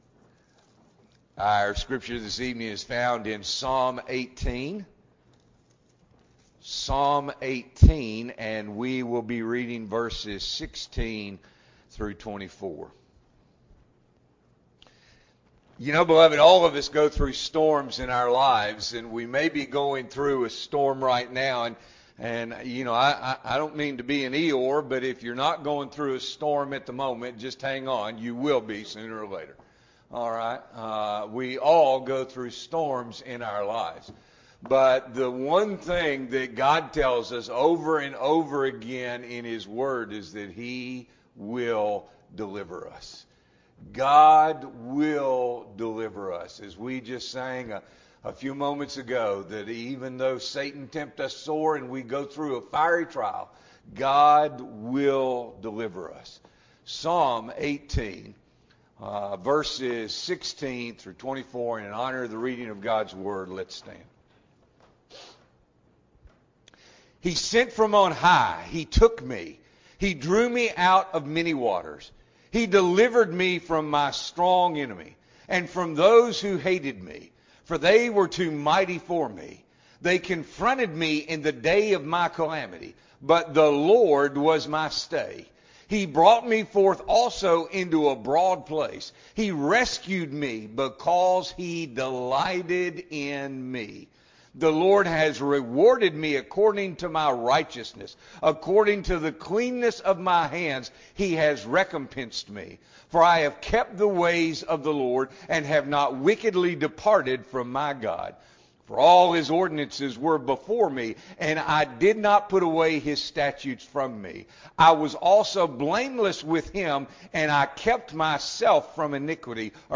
March 26, 2023 – Evening Worship